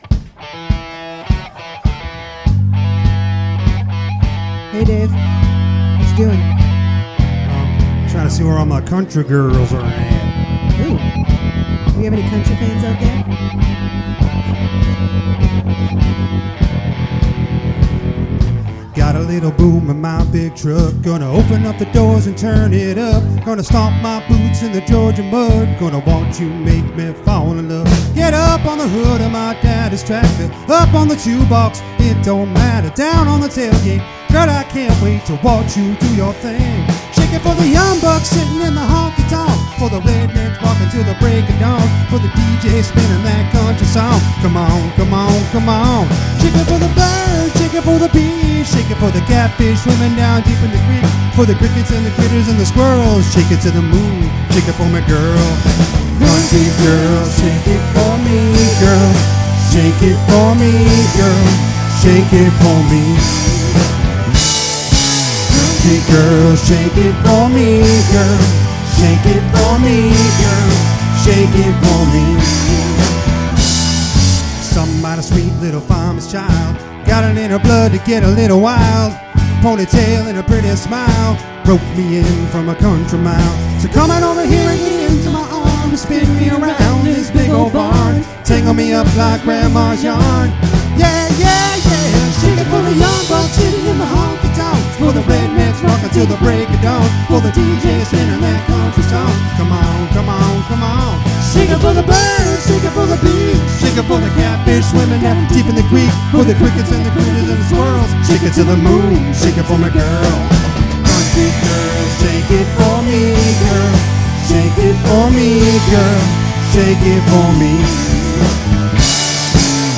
Here's a few live samples of our show.